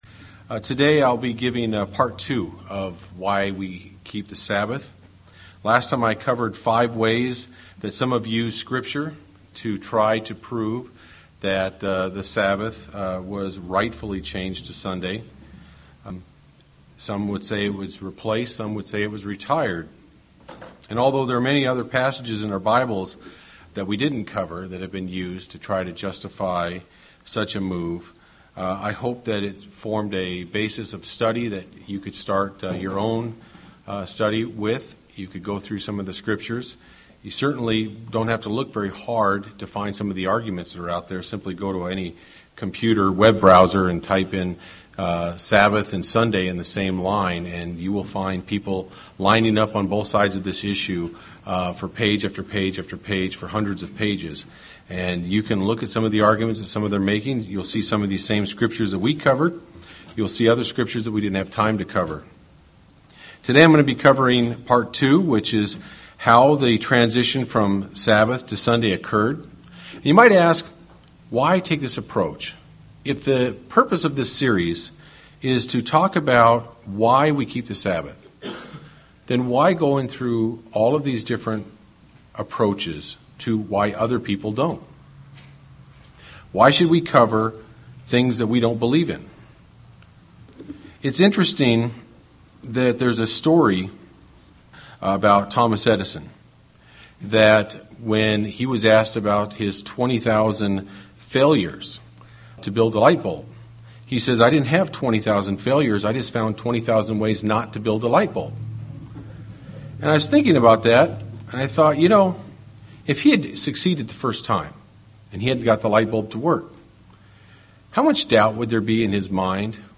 Given in Central Oregon
UCG Sermon Studying the bible?